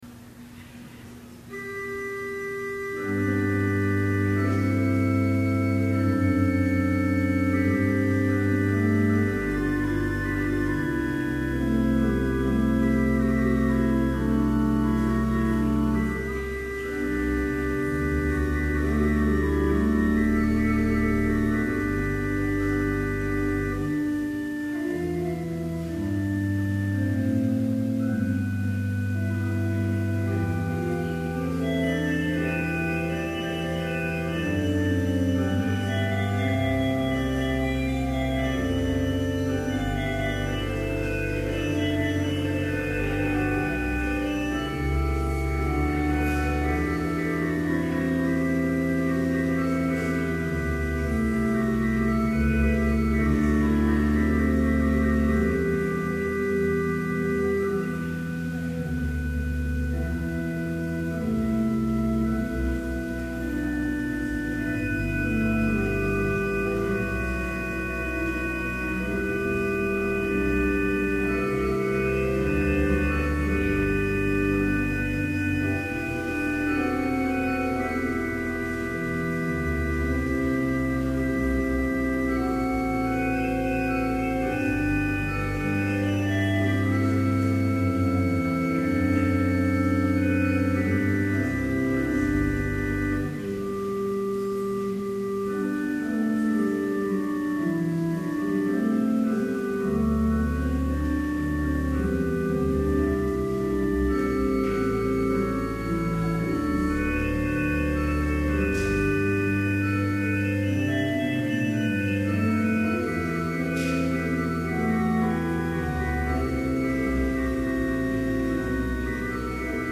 Complete service audio for Summer Chapel - June 8, 2011